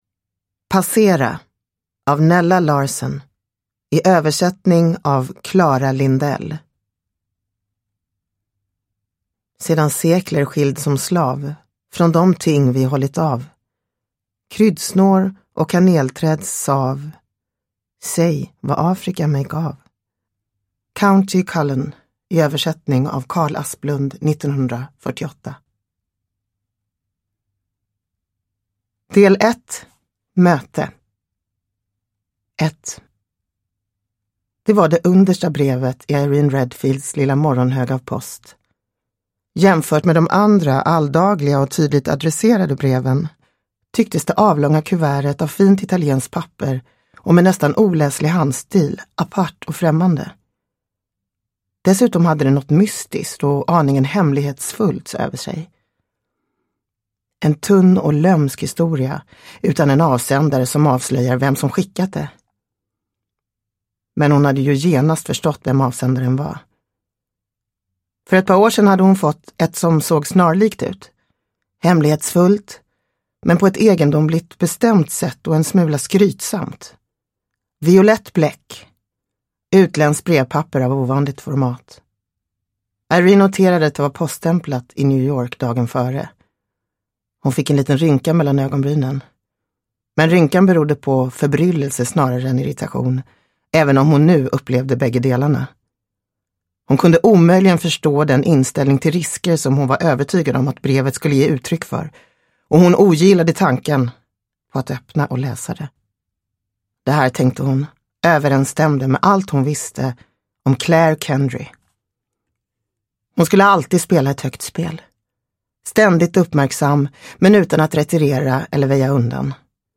Passera – Ljudbok – Laddas ner